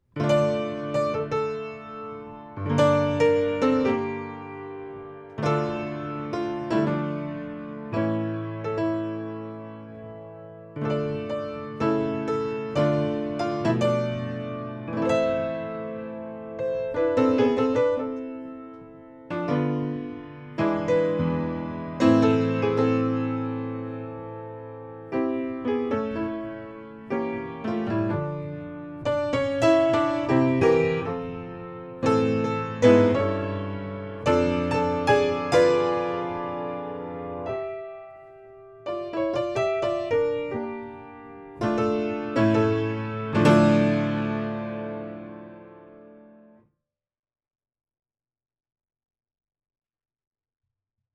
Notes: for piano
Hungarian Song or Romance–C.